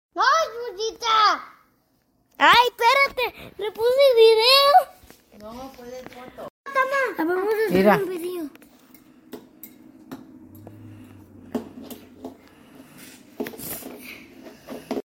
funny sound hahaha